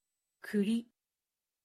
Prononciation-de-kuri.mp3